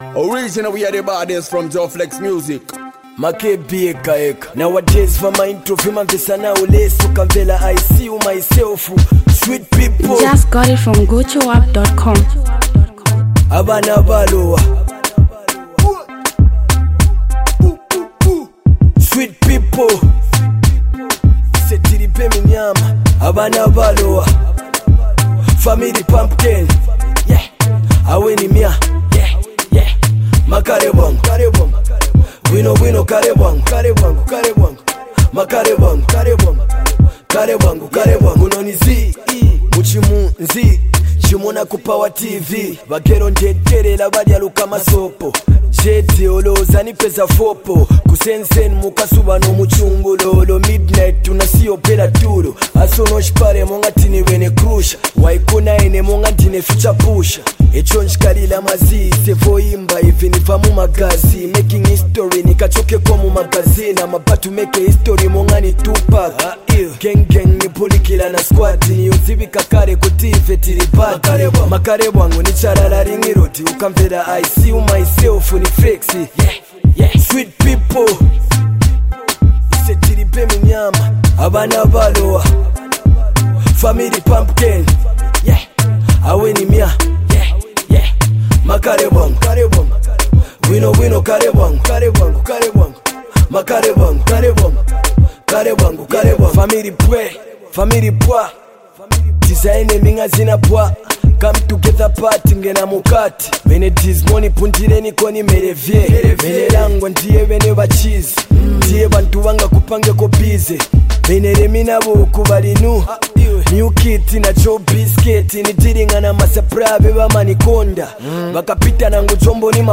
a rising star in Zambian music
sizzling track